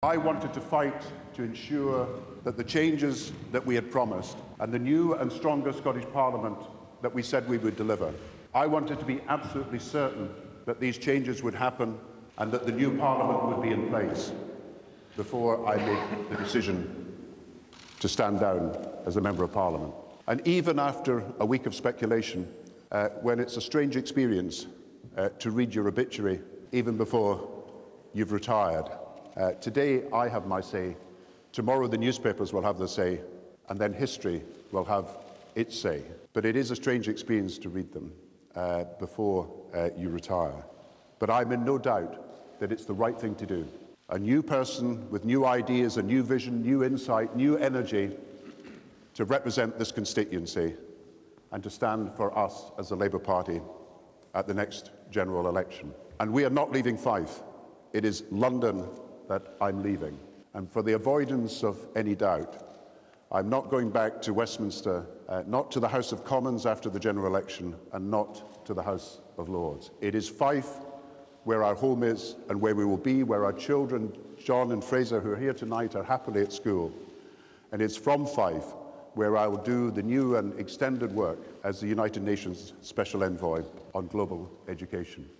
He put an end to months of speculation last night during an address to Labour members in Kirkcaldy.
Making the announcement at a packed Old Kirk in the lang toun last, Mr Brown said it was time for fresh ideas: